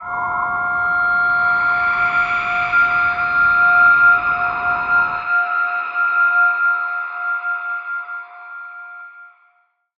G_Crystal-F7-mf.wav